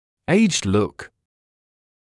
[‘eɪʤɪd luk][‘эйжд лук]пожилой вид; внешность пожилого или старого человека